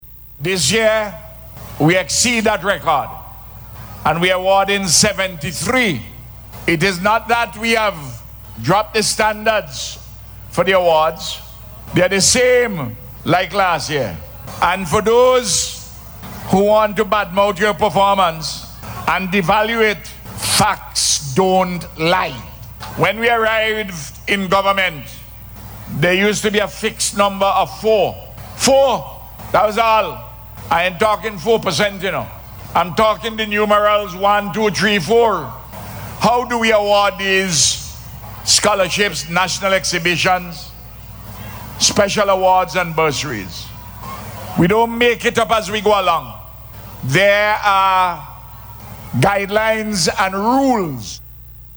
In his address at the Rally, Prime Minister Gonsalves said an additional twelve students received awards this year.